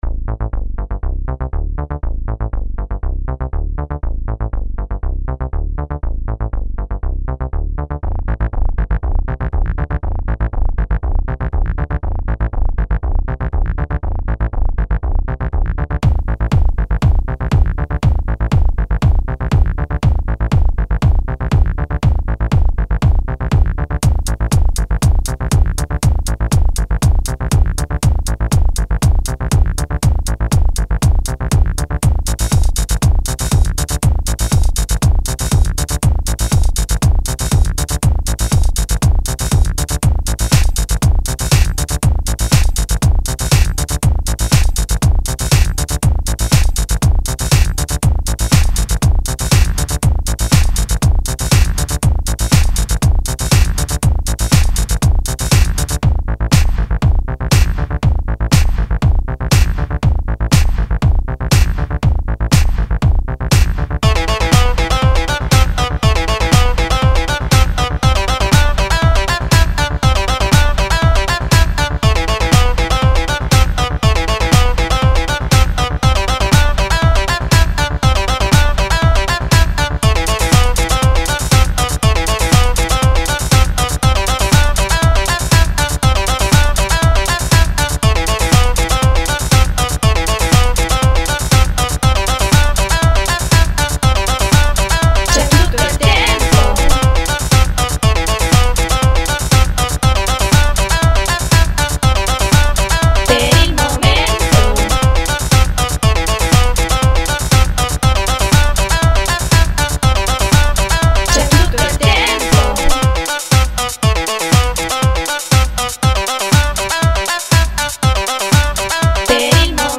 a colpi di filo spinato vocale
disco